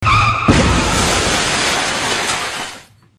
CAR CRASH